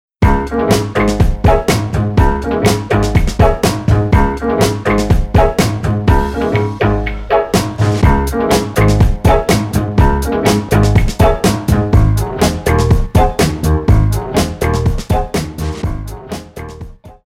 Мы предлагаем Вам отделить голос от музыки на уровне акустического спектра.
Минус